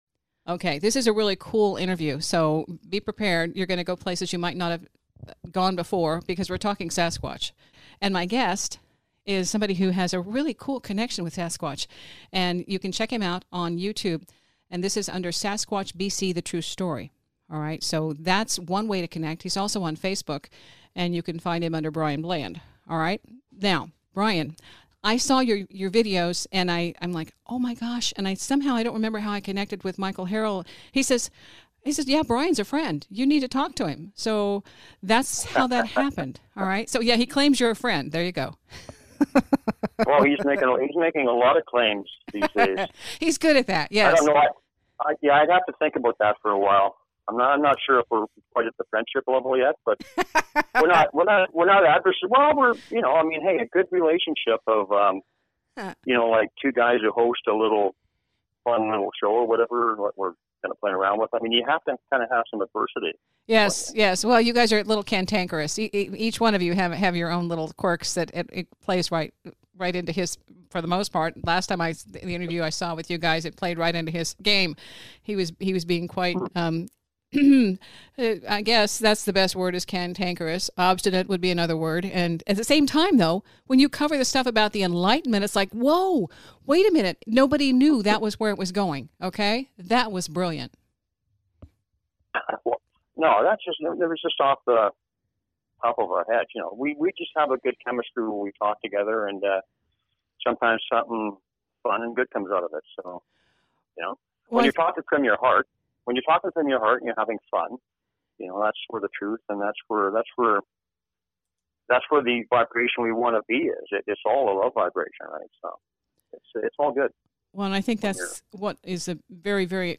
This interview aired on KCMO Talk Radio 710 AM.